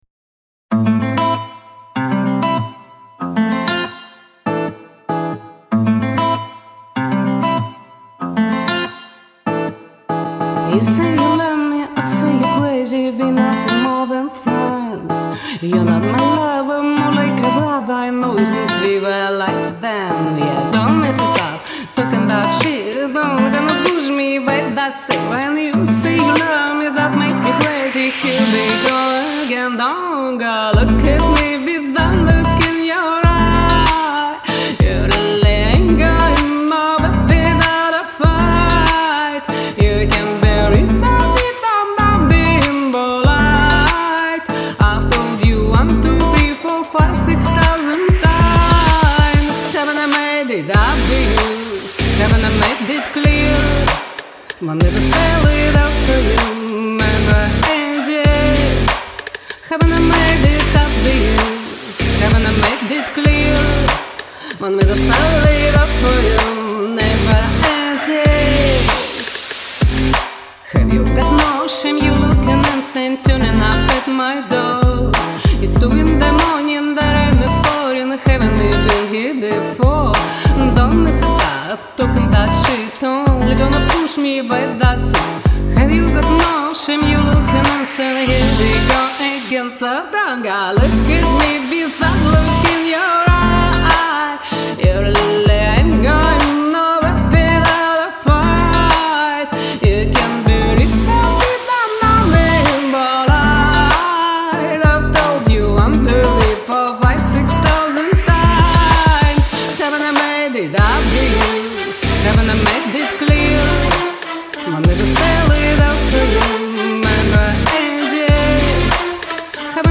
весёленькое, ритмичное.